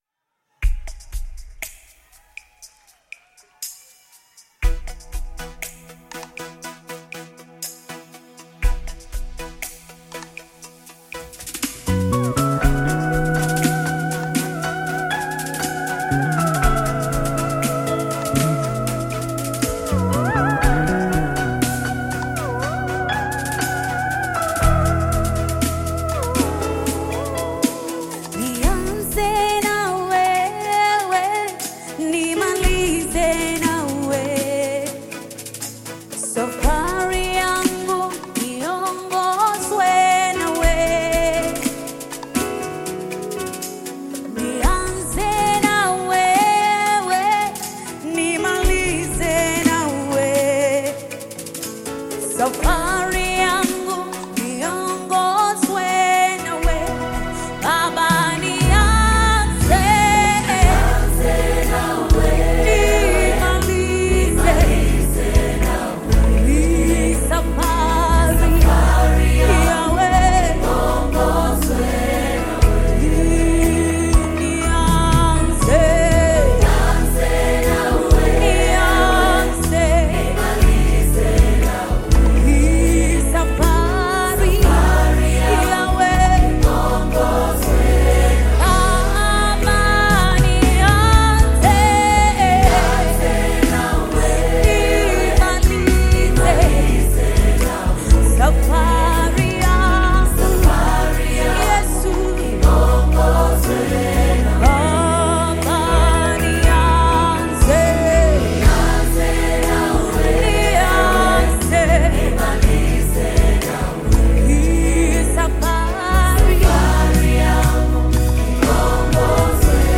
Gospel music track
Tanzanian gospel group